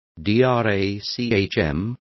Complete with pronunciation of the translation of drachm.